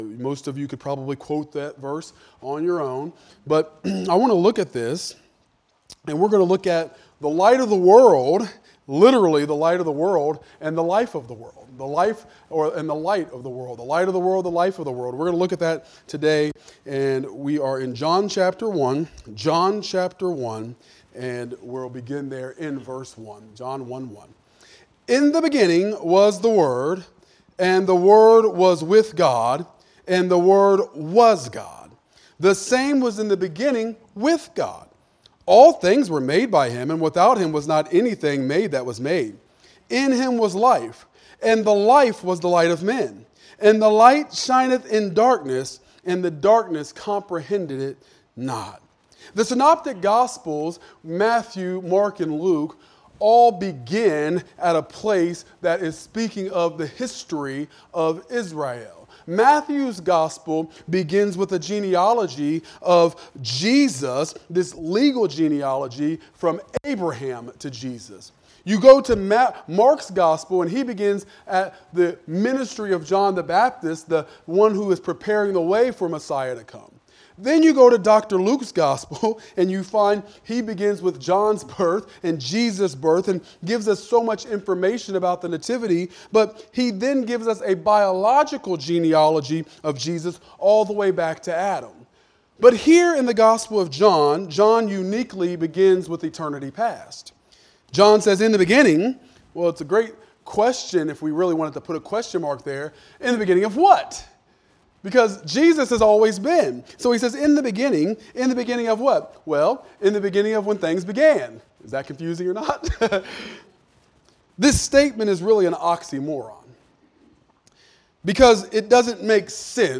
Wednesday Bible Study